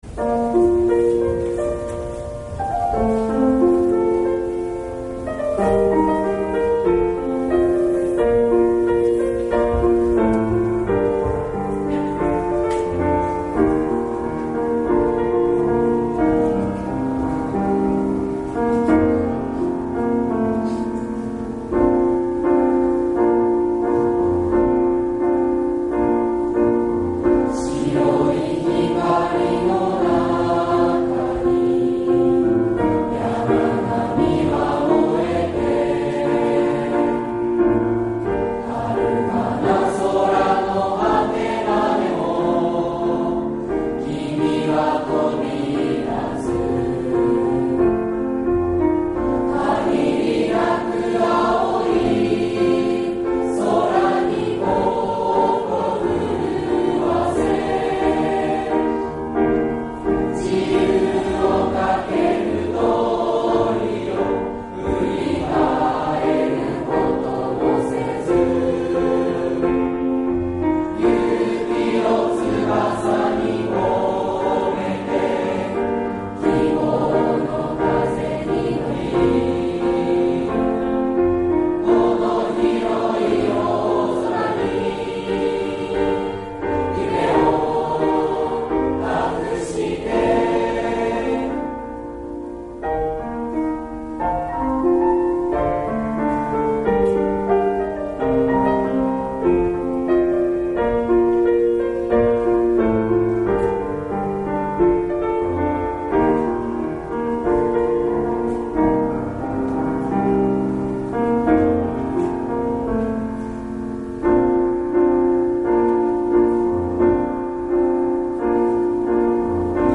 卒業の歌「旅立ちの日に」
第54回卒業証書授与式「旅立ちの日に」